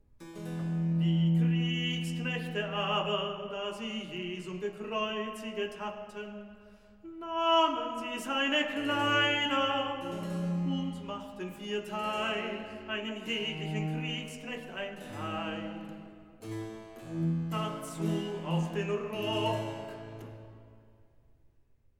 Recitativo evangelist